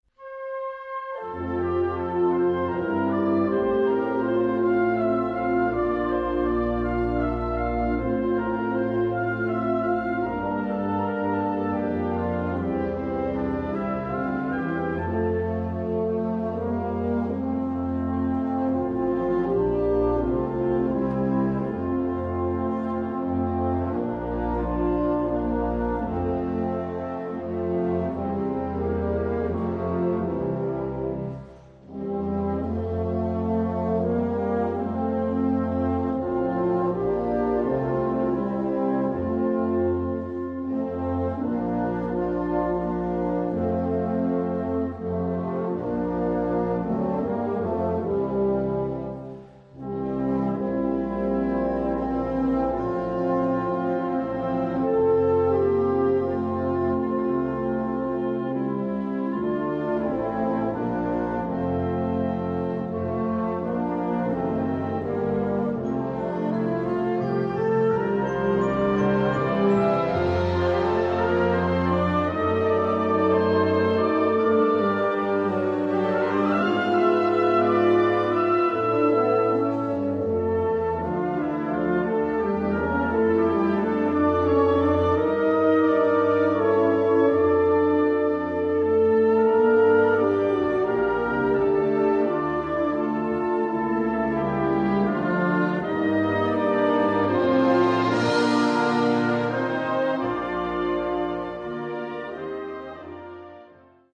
Gattung: Festliche Musik
Besetzung: Blasorchester